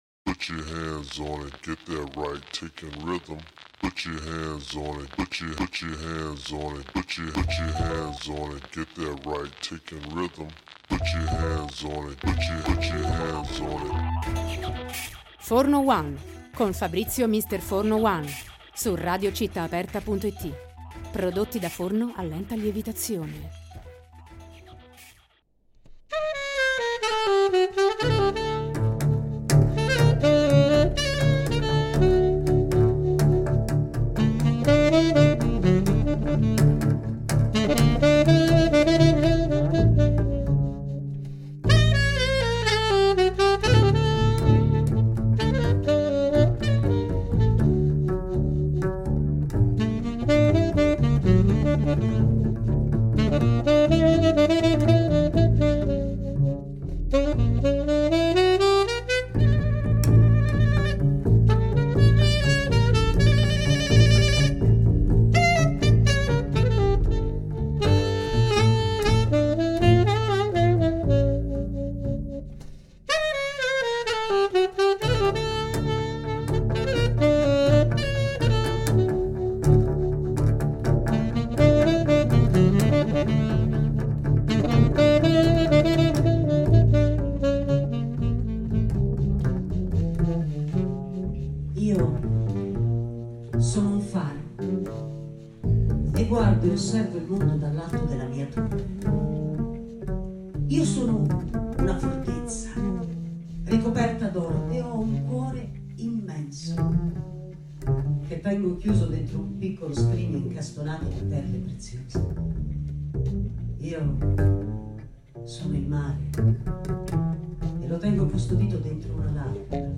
Intervista-Rossellini-Spazio-Jazz-2026.mp3